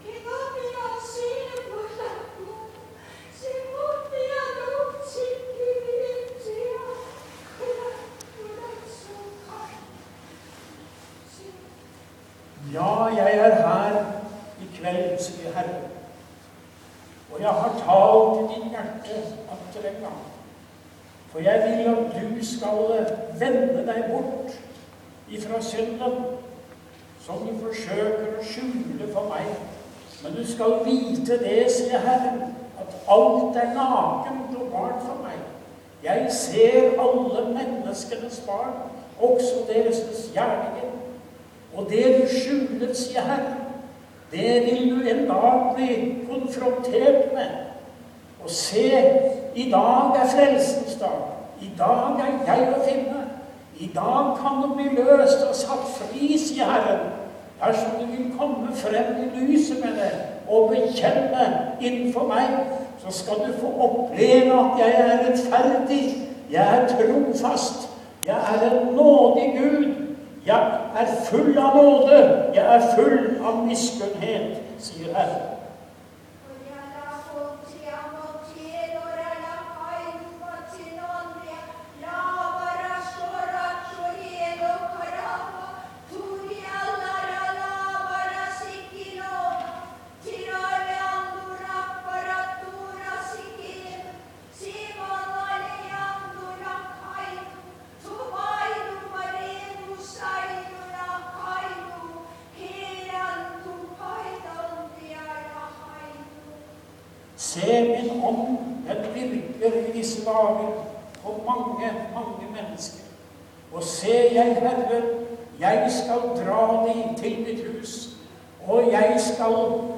Tungetale